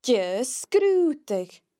The broad sgr sound can also be heard in sgrùdadh (an investigation):